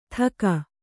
♪ thaka